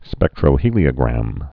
(spĕktrō-hēlē-ə-grăm)